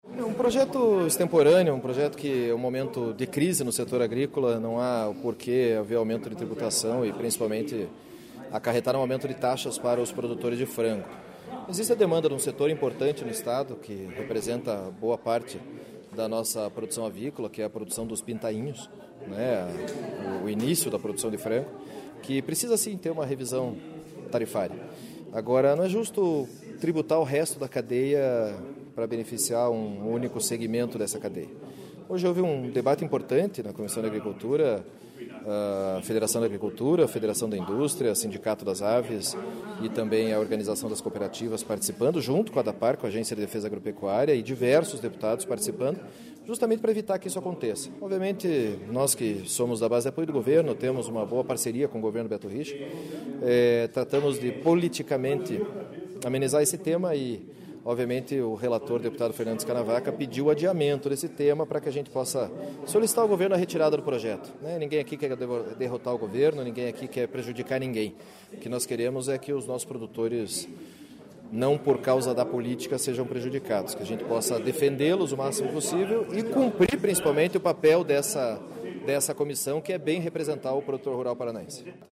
O presidente da comissão, deputado Pedro Lupion (DEM), fala sobre o projeto e a decisão do grupo de adiar a votação do relatório.